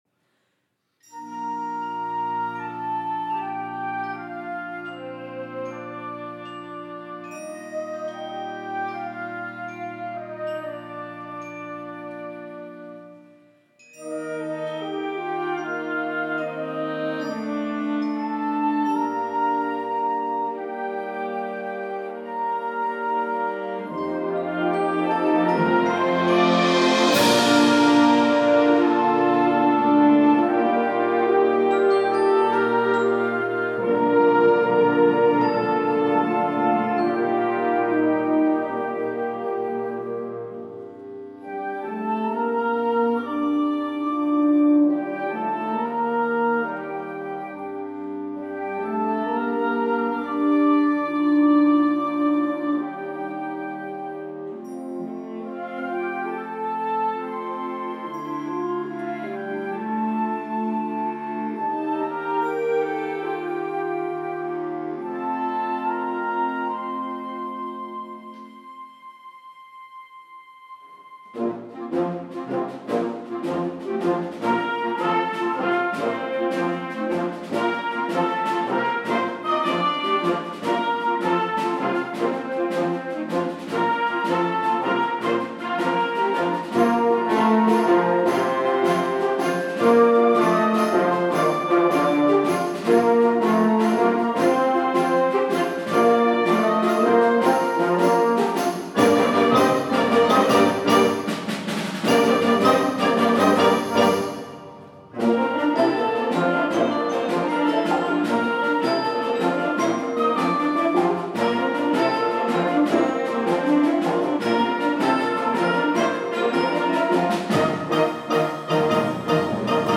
Genre: Band
is a touching overture
Flute
Tuba
Timpani (2 drums)
Percussion 1 & 2: Snare Drum, Bass Drum